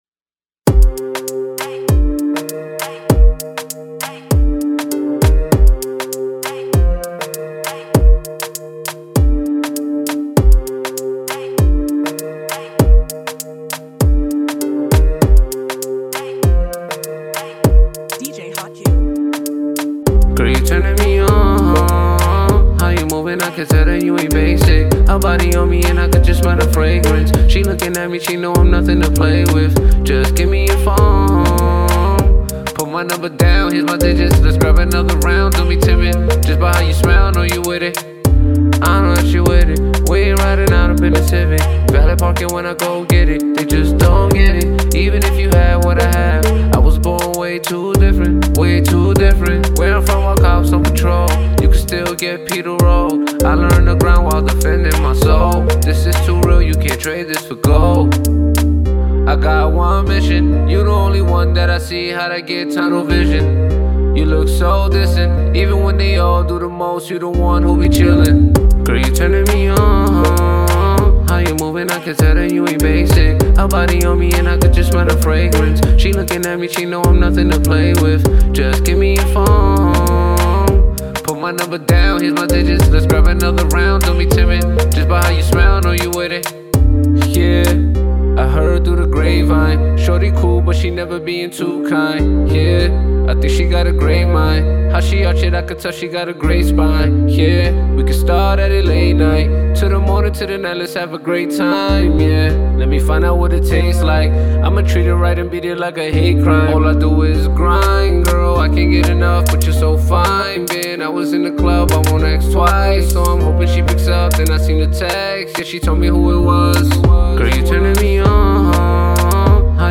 A dope Caribbean vibe track to start your summer of right!